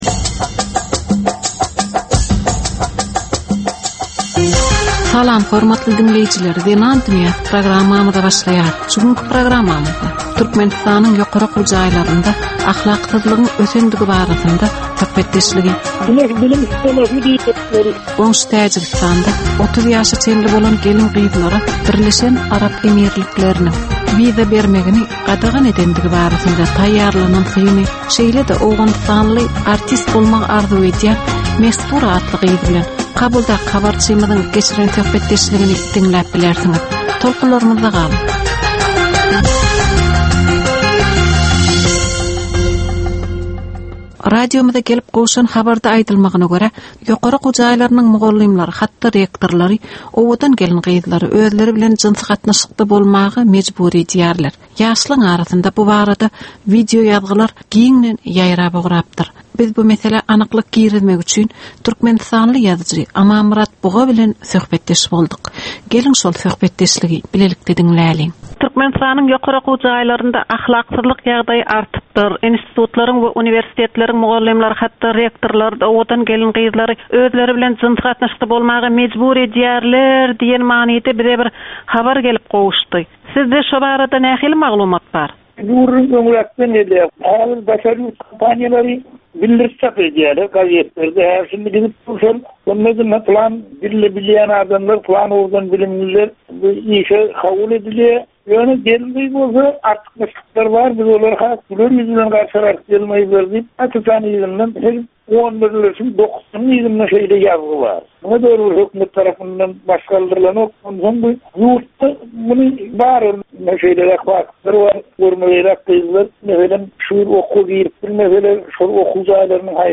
Türkmen we halkara aýal-gyzlarynyn durmusyna degisli derwaýys meselelere we täzeliklere bagyslanylyp taýýarlanylýan 15 minutlyk ýörite geplesik. Bu geplesiklde aýal-gyzlaryn durmusyna degisli maglumatlar, synlar, bu meseleler boýunça synçylaryn we bilermenlerin pikrileri, teklipleri we diskussiýalary berilýär.